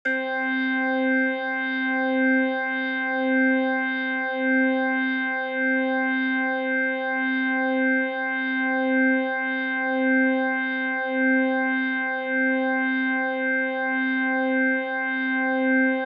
MB Organ.wav